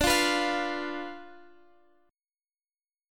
Listen to Dm6 strummed